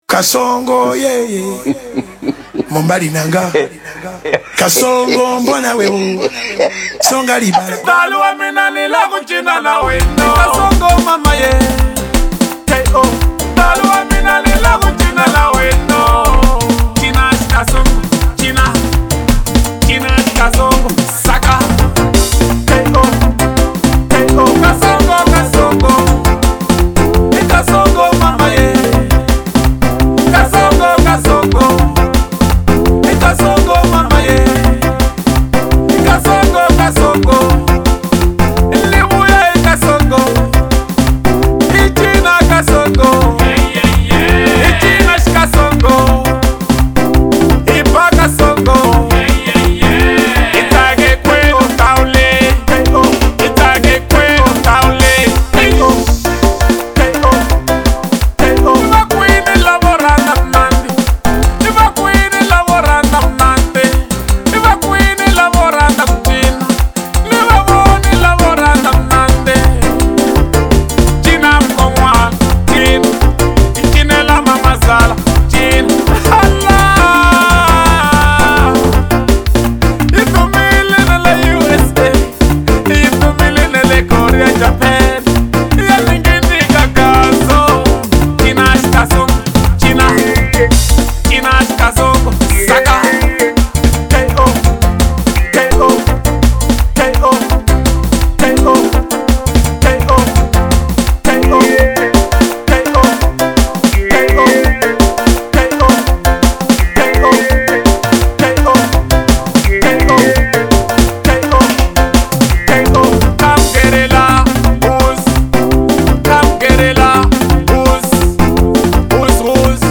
04:30 Genre : Xitsonga Size